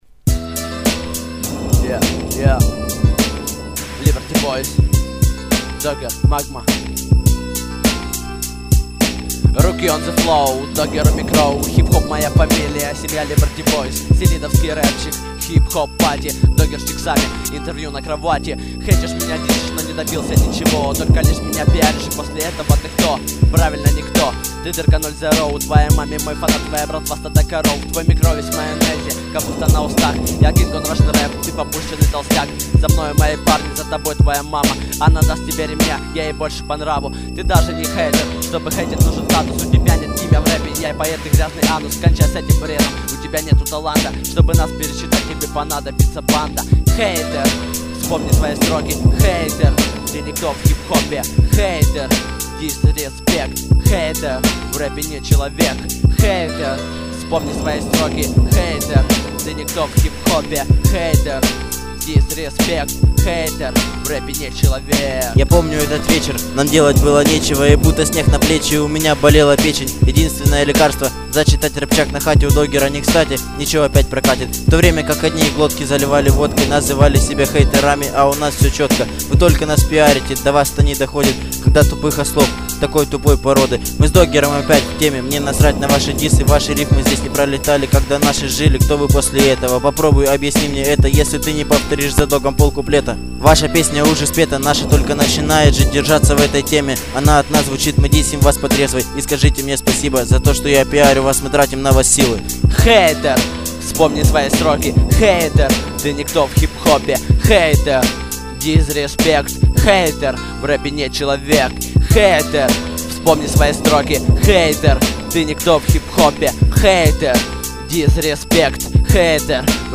рэп тексты
Премьера сингла